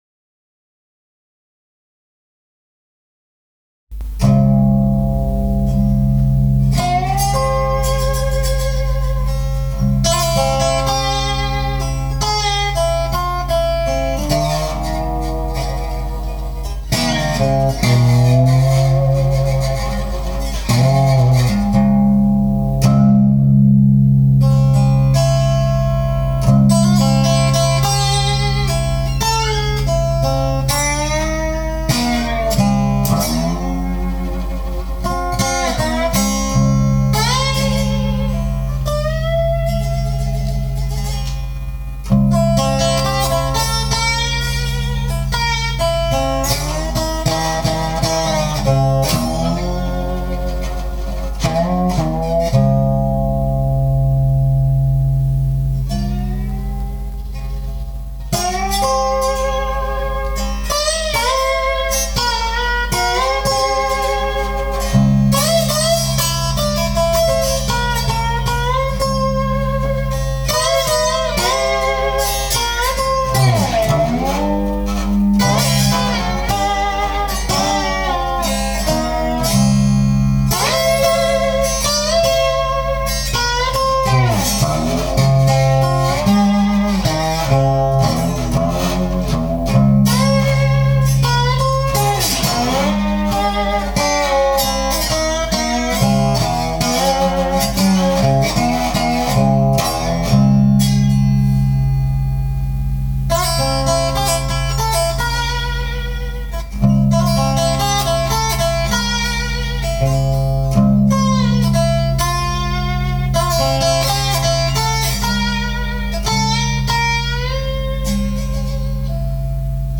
slide guitar solo